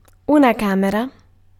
Ääntäminen
IPA : [ə.ˈsɛm.bli]